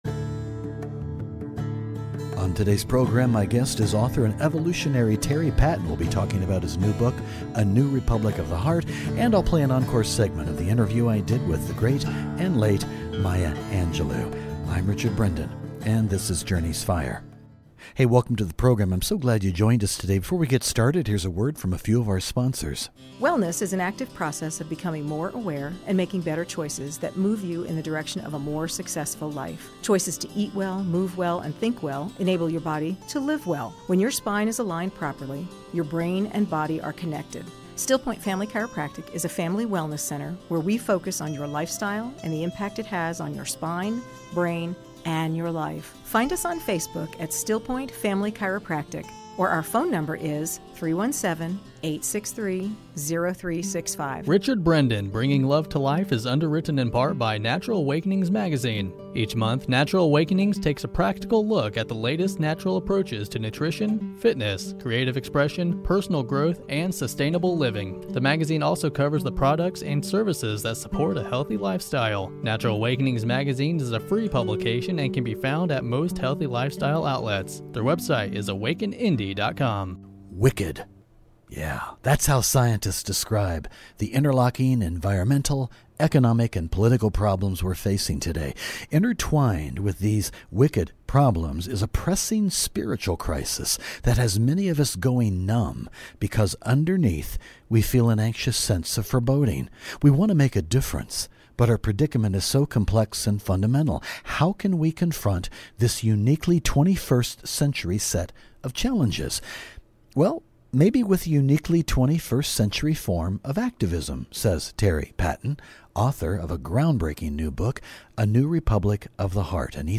I invite you to listen to our lively, engaging dialogue about a New Republic of the Heart and the movement that is building from a revolution of love.